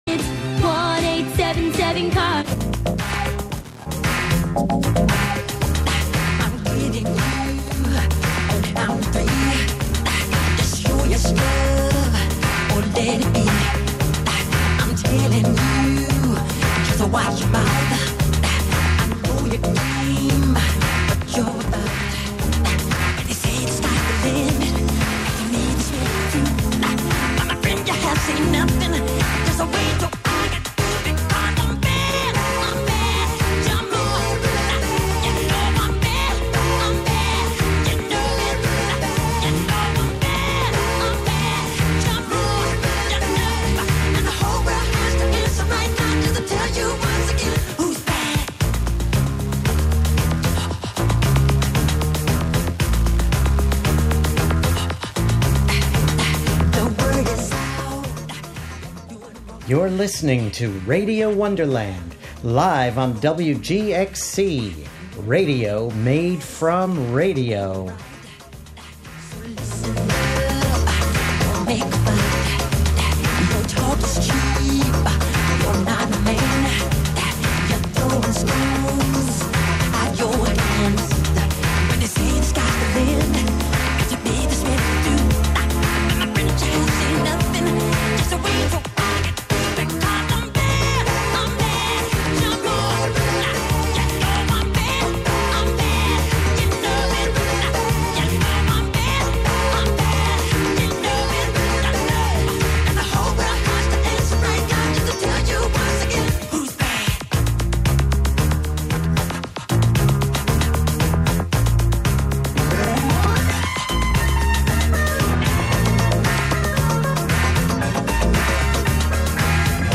Live from Brooklyn, New York,